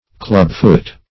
Clubfoot \Club"foot\, n. [Club + foot.] (Med.)